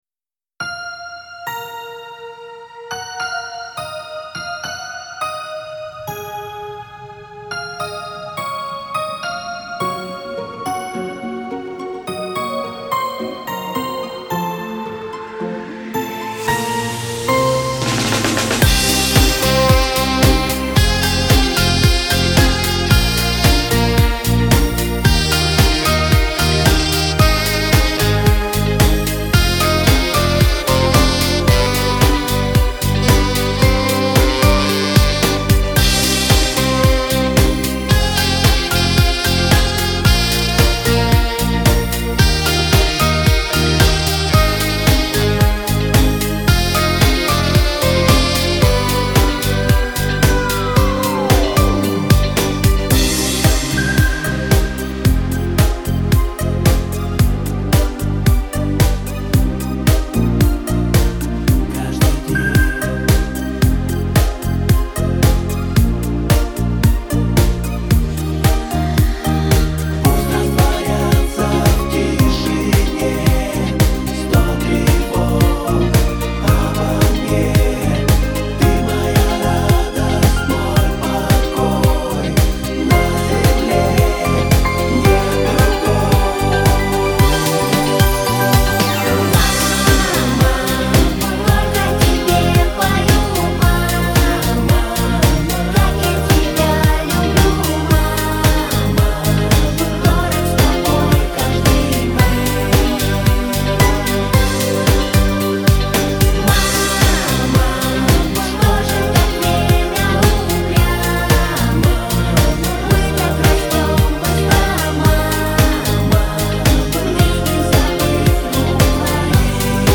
Слушать или скачать минус